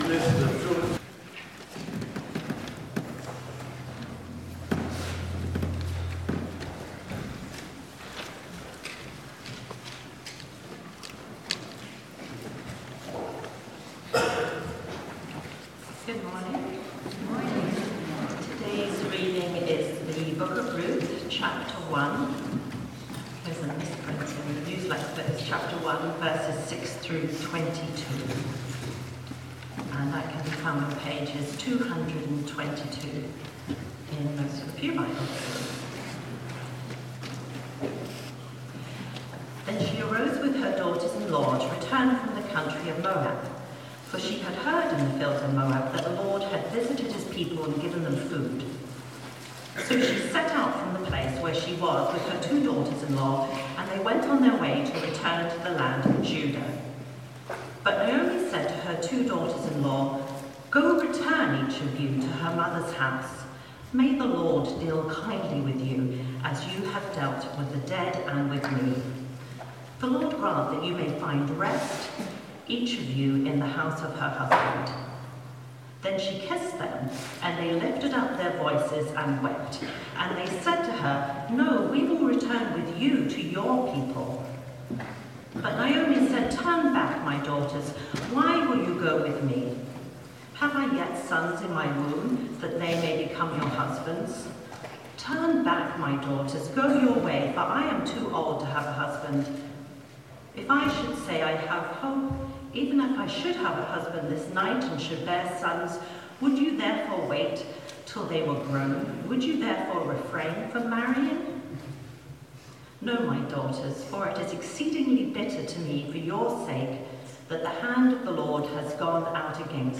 Passage: Ruth 1:6-22 Sermon